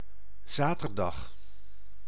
Ääntäminen
Ääntäminen France (Ouest): IPA: /sam.di/ France: IPA: [sam.di] Haettu sana löytyi näillä lähdekielillä: ranska Käännös Ääninäyte Substantiivit 1. zaterdag {m} 2. sabbat {m} Suku: m .